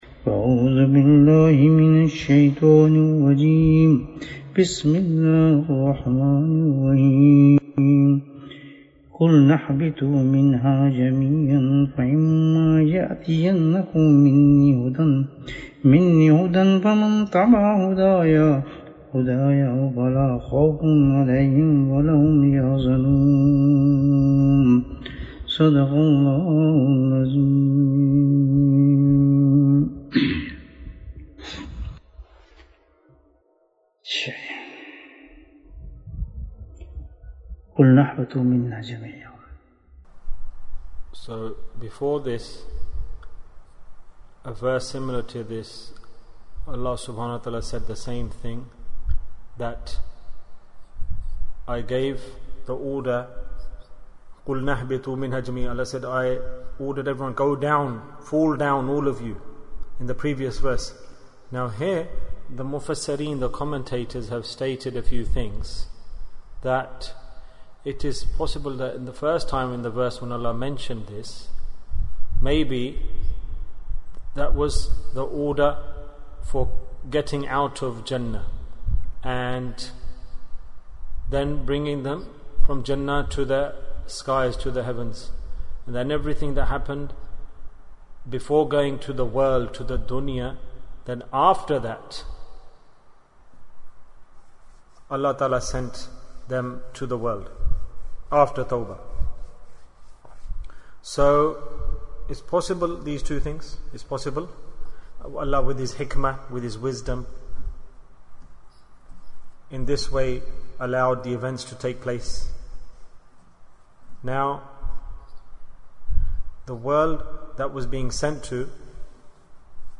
Proper Way of Tawbah - Dars 44 Bayan, 53 minutes12th August, 2020